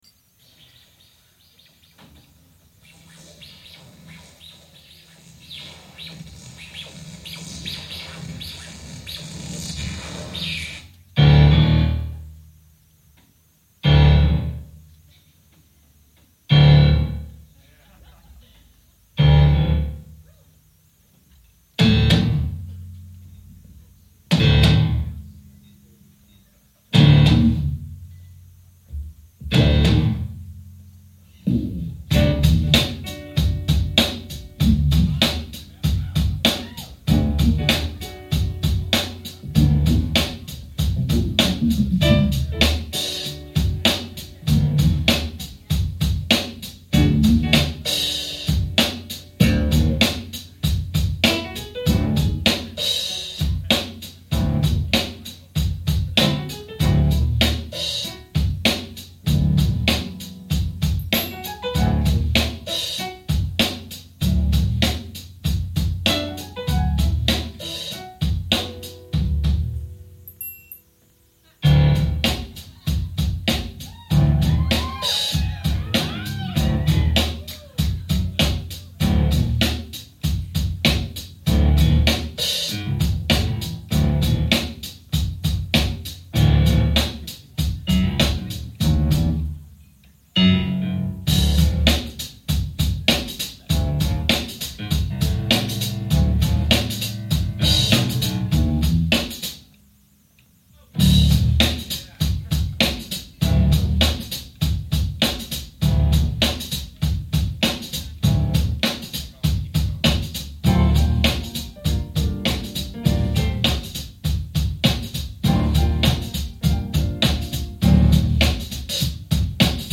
Muse 2 The Pharaoh (instrumental) :)